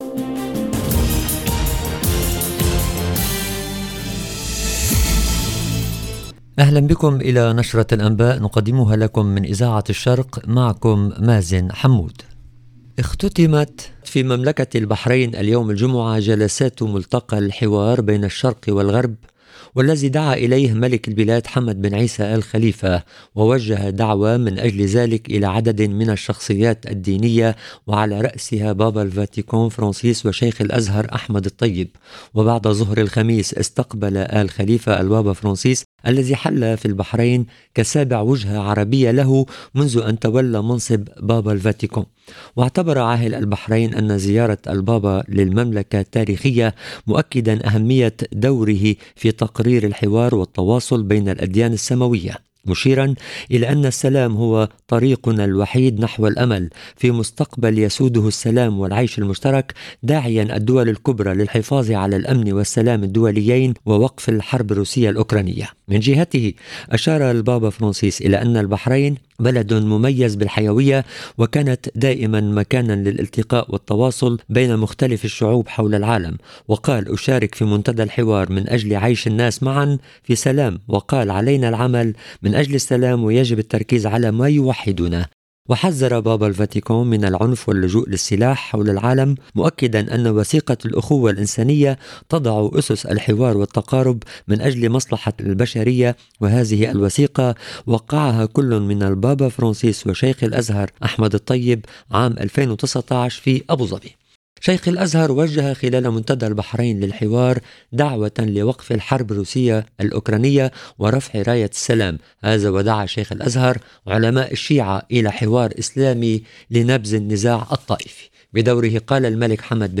EDITION DU JOURNAL DU SOIR EN LANGUE ARABE DU 4/11/2022